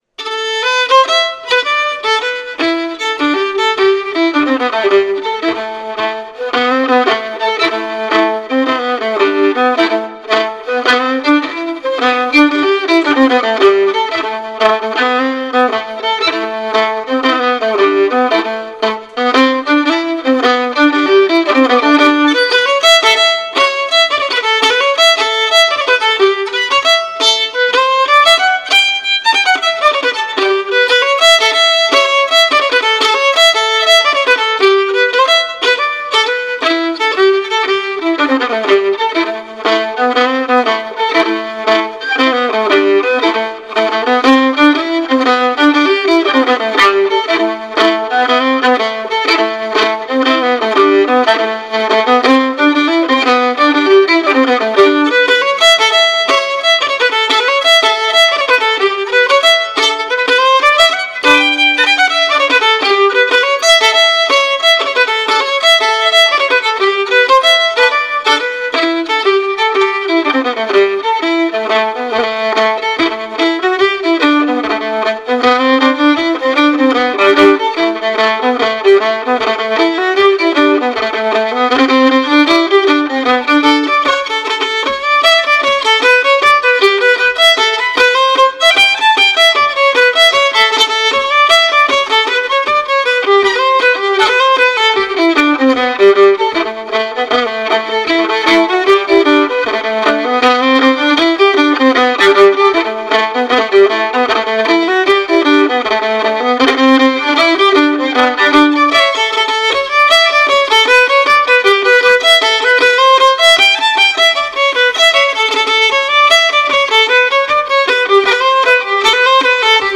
It promotes traditional Cape Breton style music through fiddle, guitar, piano, singers, step dancers, and lovers of Cape Breton Fiddle Music.